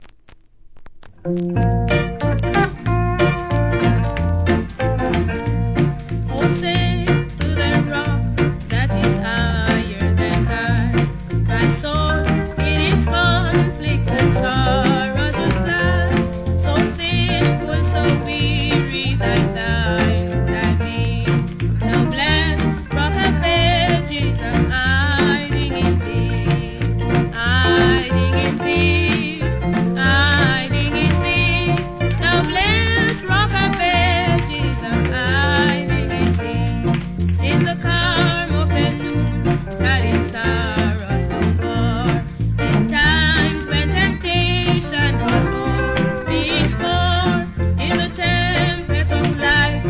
1. REGGAE
1974年、中毒性の高いカントリー＆ワルツなメント・カリプソ作！